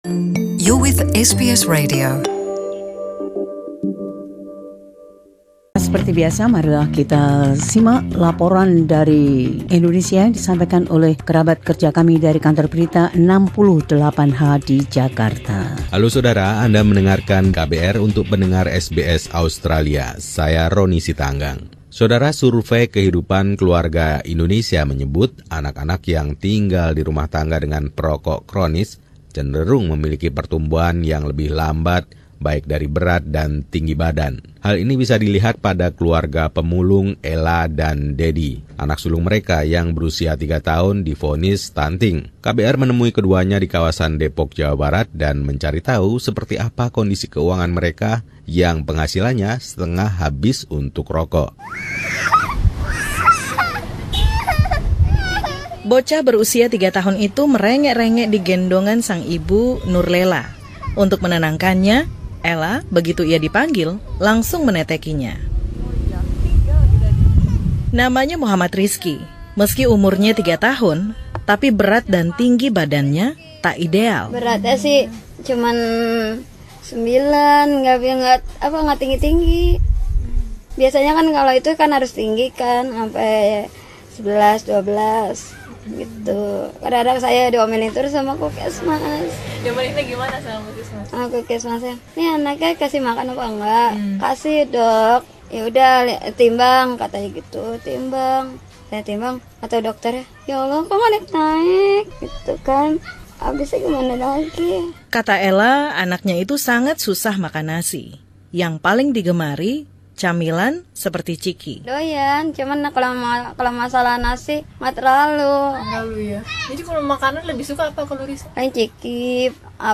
This special report from the team at KBR 68 H describes how low-income Indonesian smokers ensnare their families in a vicious circle that makes them even poorer and sicker.